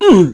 Oddy-Vox_Damage_01.wav